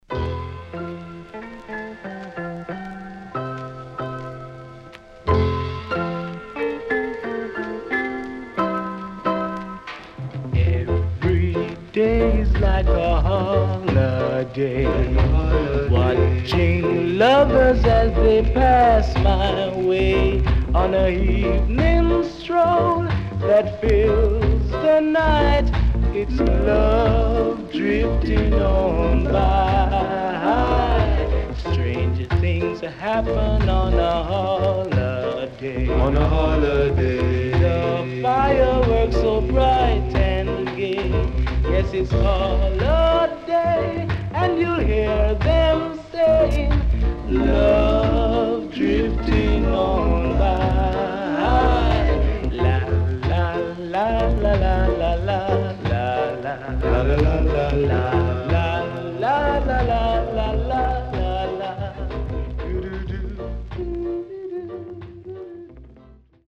Rocksteady Inst & Vocal.W-Side Good
SIDE A:所々チリノイズがあり、少しプチノイズ入ります。